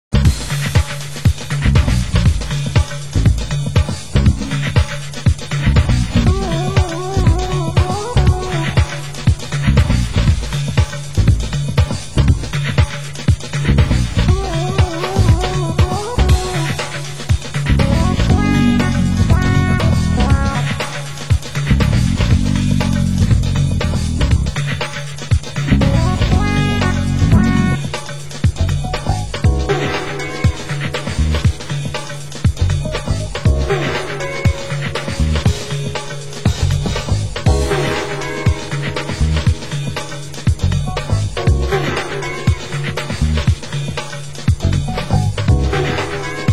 Genre Deep House